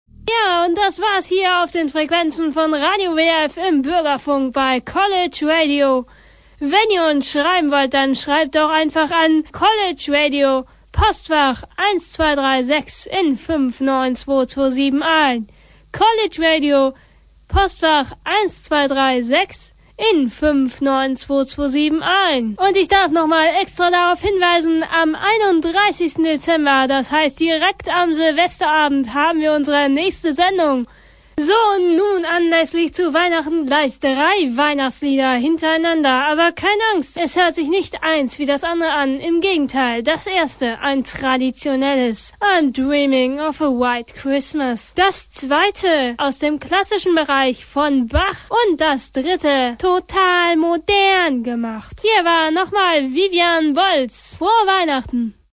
diese legendäre Stimme hier. :D